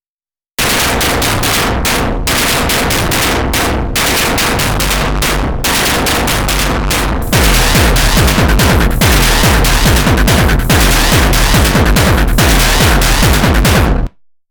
Those last two are some serious early AFX vibes.